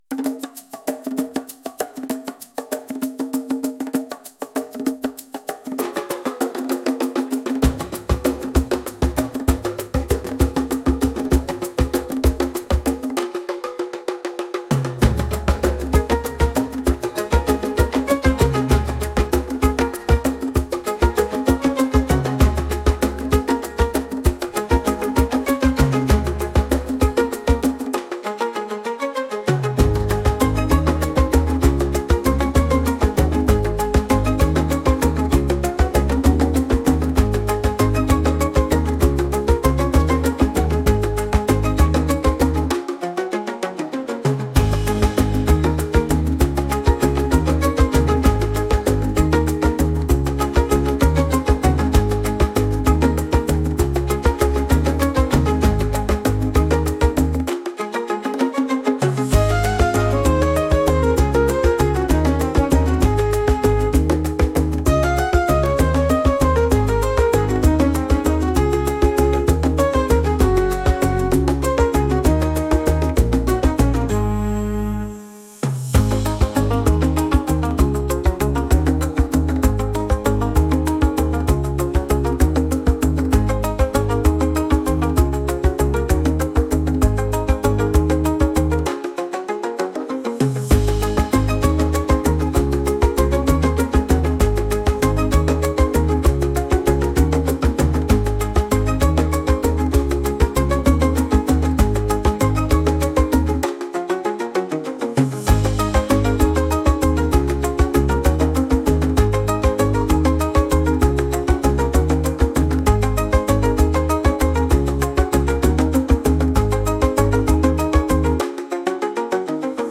Traditional Djembe rhythm with call and response patterns